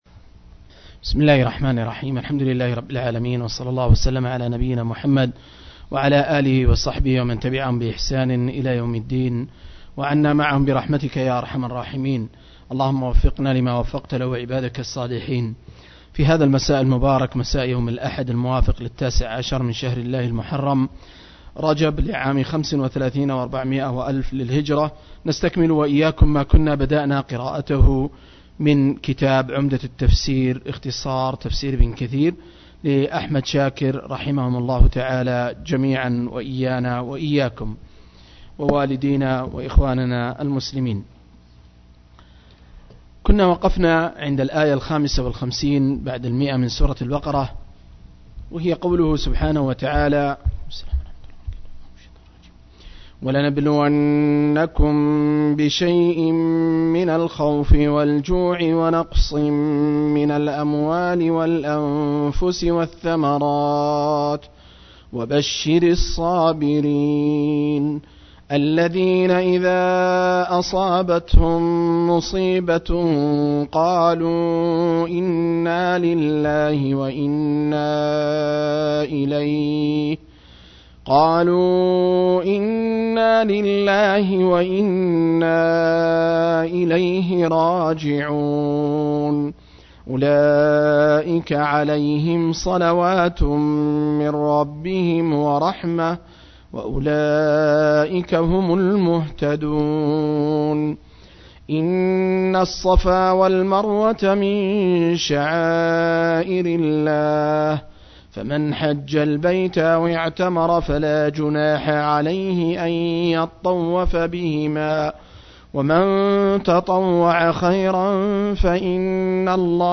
032- عمدة التفسير عن الحافظ ابن كثير – قراءة وتعليق – تفسير سورة البقرة (الآيات 163-155)